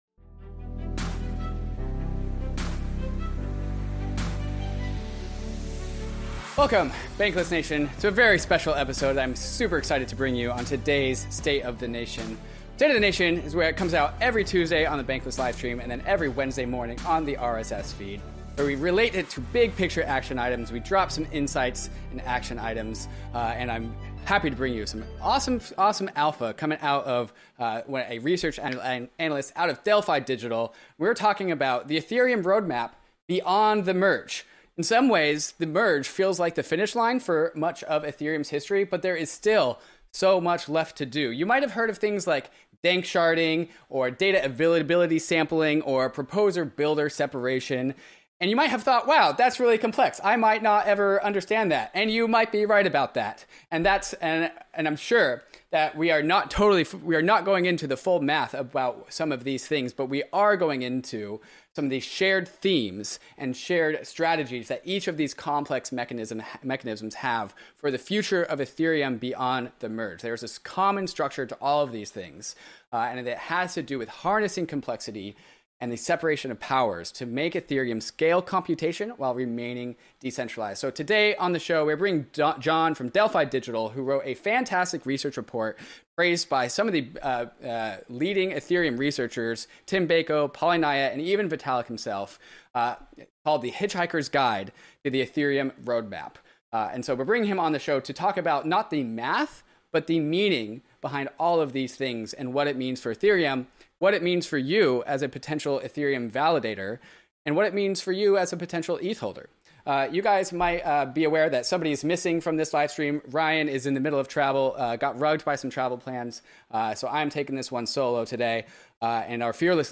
All of these questions answered and so much more in the livestream.